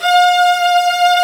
Index of /90_sSampleCDs/Roland LCDP13 String Sections/STR_Violins I/STR_Vls4 6pc
STR VIOLIN05.wav